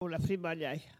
Collectif-Patois (atlas linguistique n°52)
Catégorie Locution